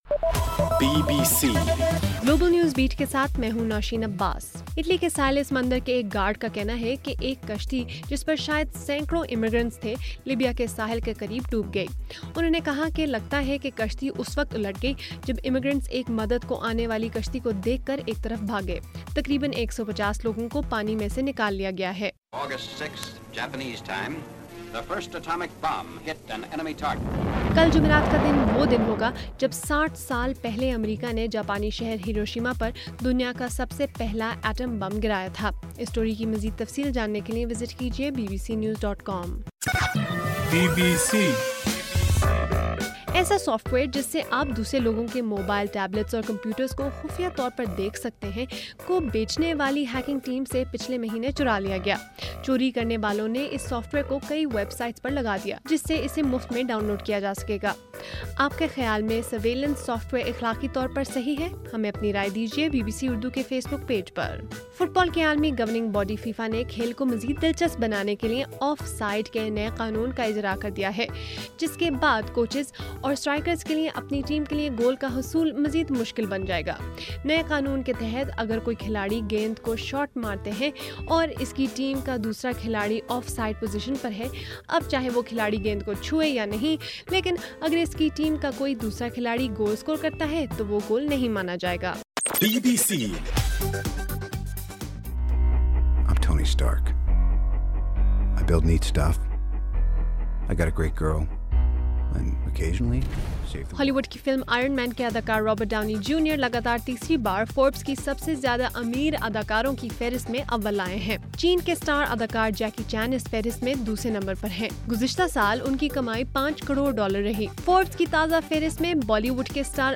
اگست 6: صبح 1 بجے کا گلوبل نیوز بیٹ بُلیٹن